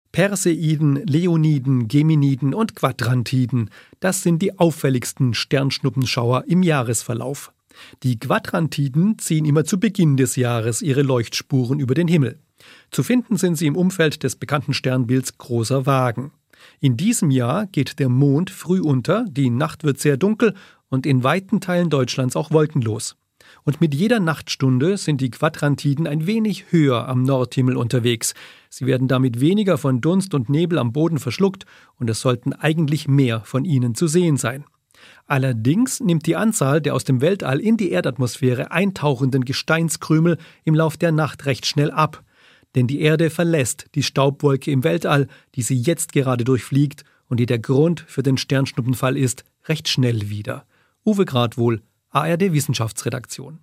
Nachrichten Quadrantiden-Sternschnuppen am Nachthimmel zu sehen